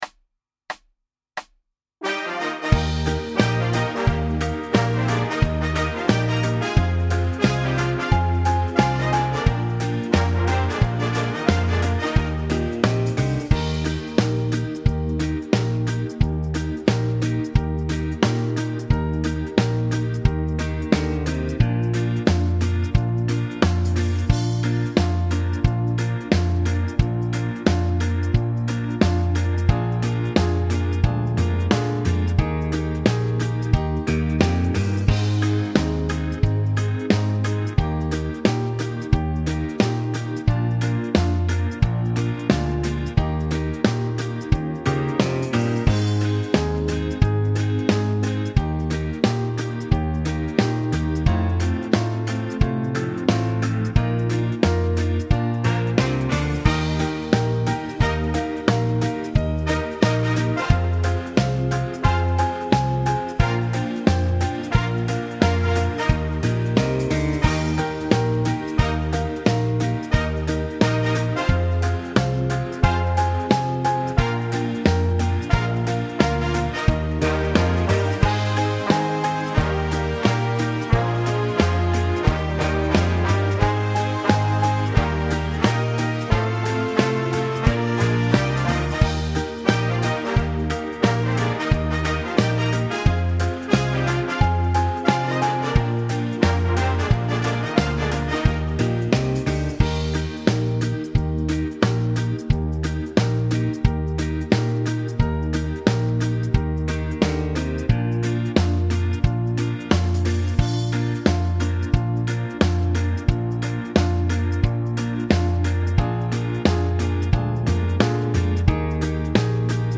Genere: Cha cha cha
Scarica la Base Mp3 con Cori (5,99 MB)